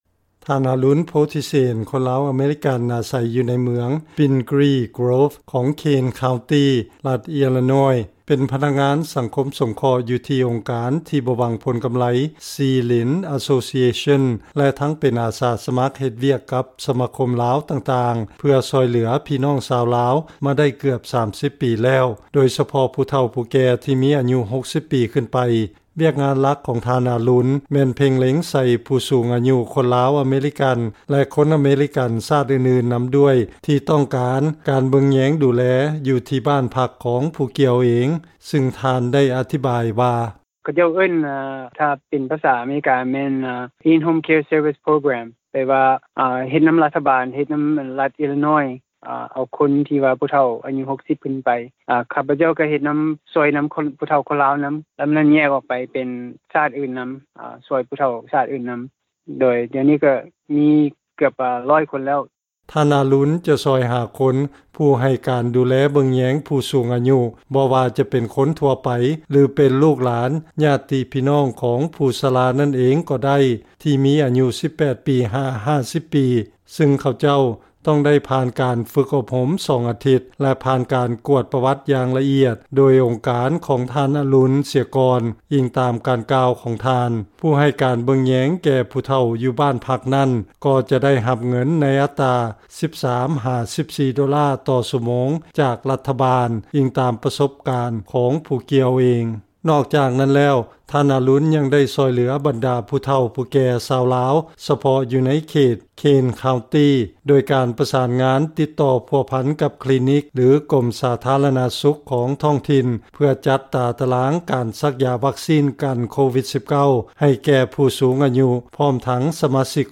ຟັງລາຍງານ ພະນັກງານສັງຄົມສົງເຄາະ ຊ່ອຍເຫຼືອຜູ້ສູງອາຍຸຊາວລາວແລະຊາດອື່ນ ໃນການຈັດຫາຜູ້ເບິ່ງແຍງ ແລະການສັກຢາວັກຊີນ